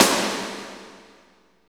53.07 SNR.wav